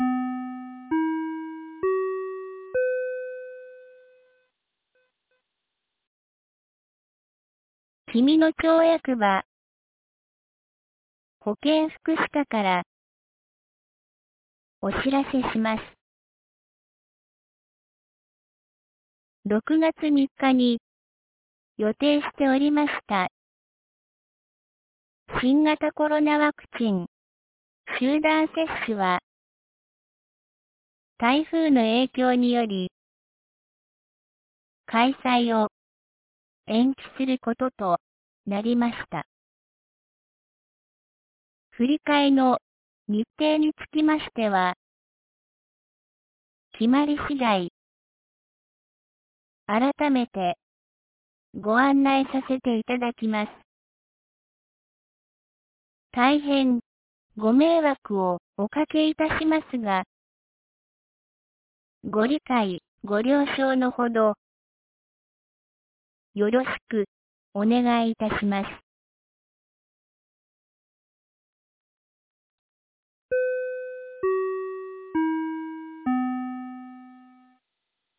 2023年06月03日 08時31分に、紀美野町より全地区へ放送がありました。